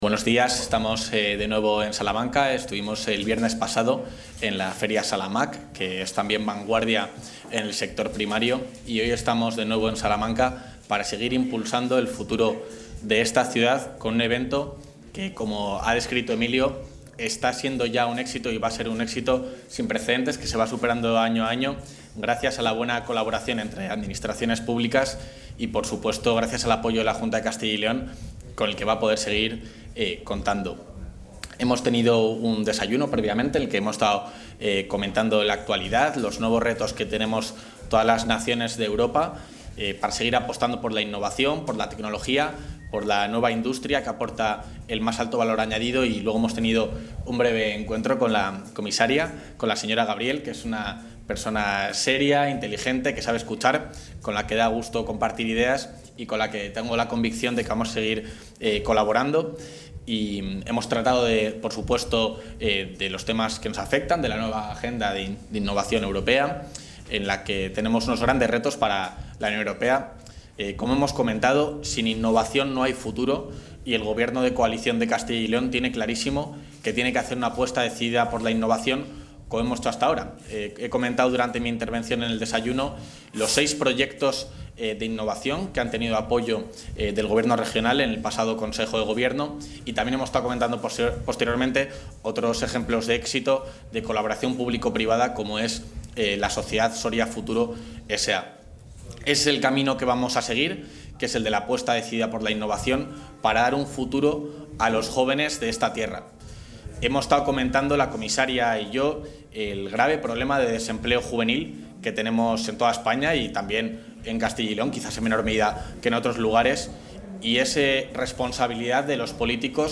Intervención del vicepresidente.
El vicepresidente de la Junta, Juan García-Gallardo, ha participado durante la mañana de hoy en los actos de inauguración de la octava edición de la Startup OLÉ, un encuentro tecnológico sobre empresas emergentes, emprendimiento e innovación referente para el sector.